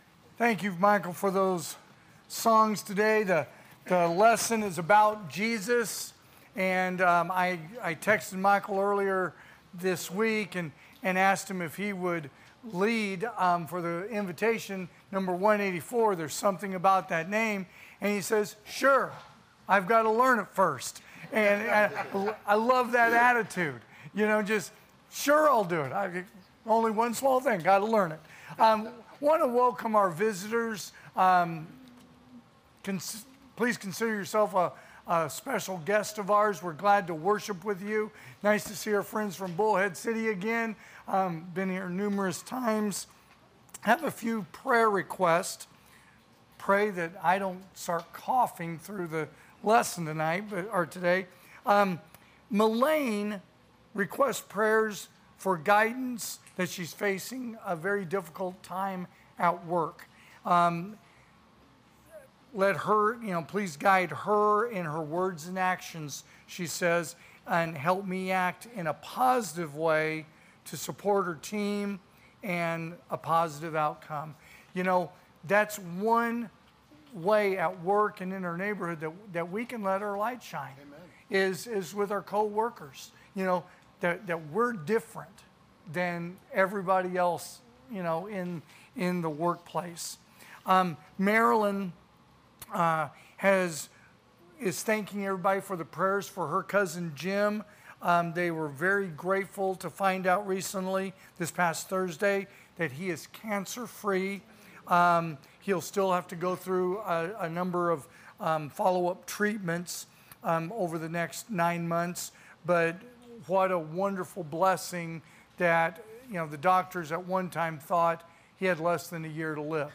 2024 (AM Worship) “Baptism”